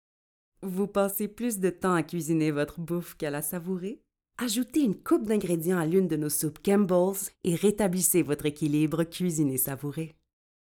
Démo de voix
Publicité Campbells - Démo Fictif